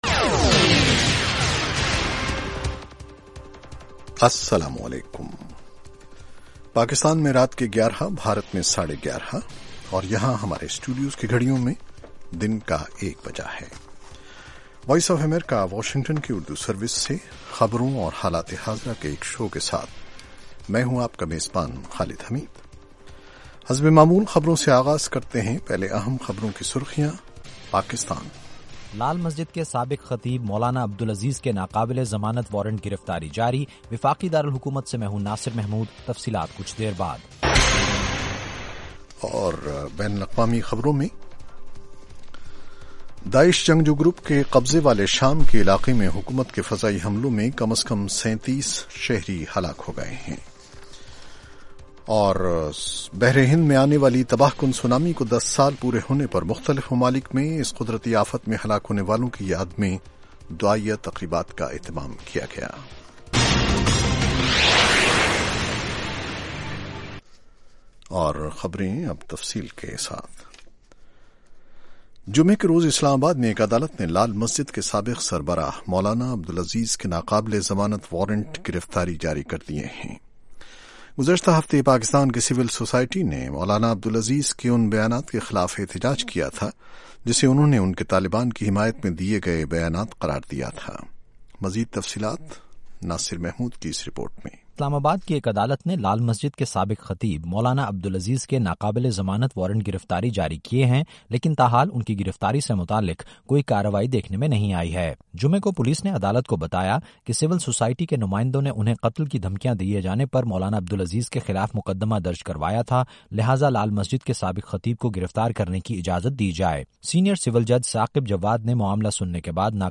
11:00PM اردو نیوز شو